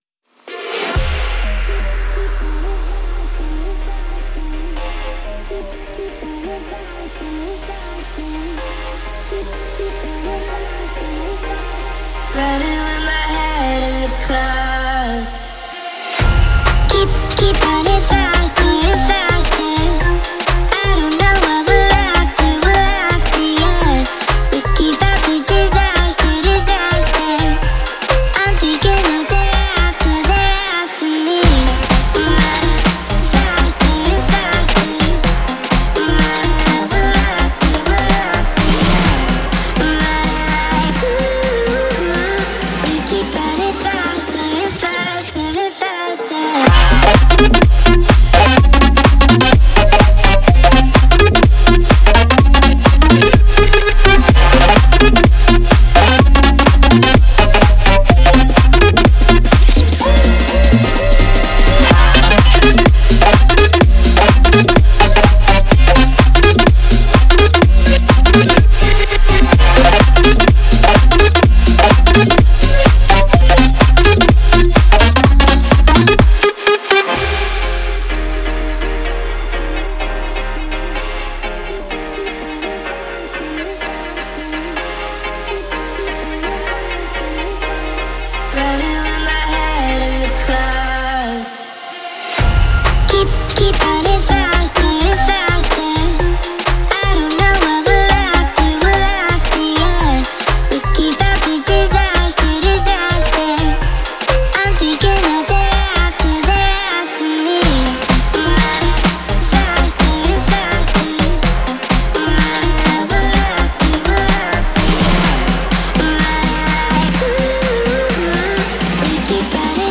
динамичная поп-рок композиция